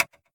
metronomeLeft.ogg